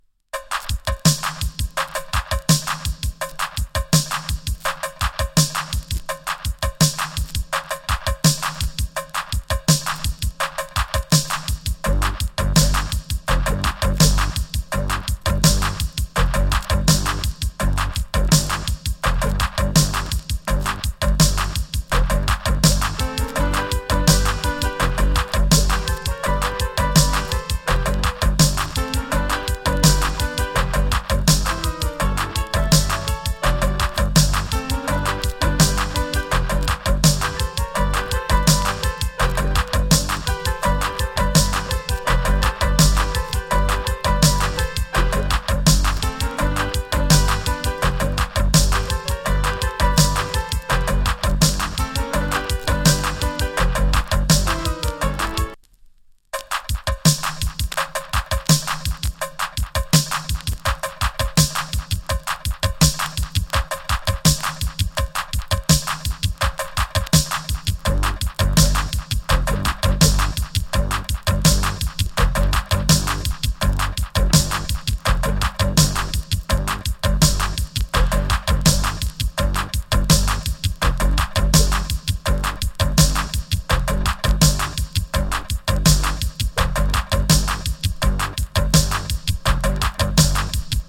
MID 80'S INST.